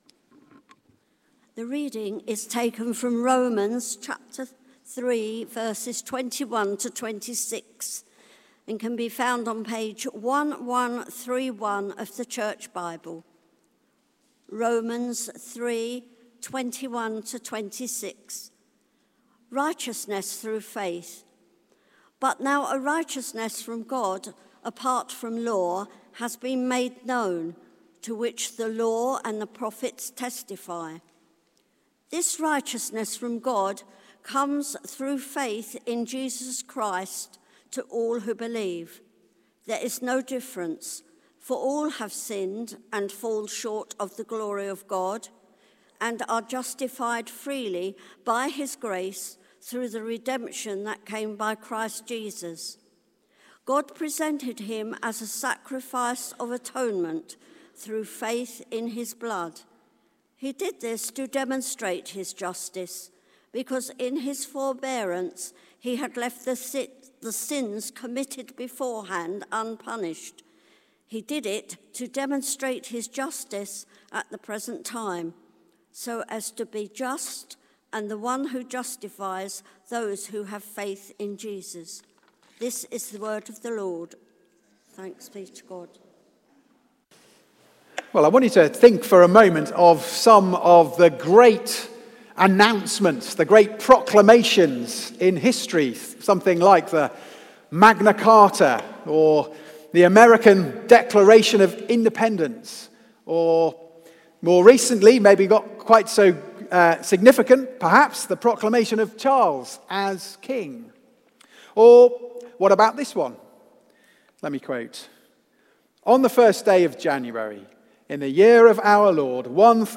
Romans: God's Glorious Gospel! Theme: God's wonderful way to make us right with him again Sermon Search: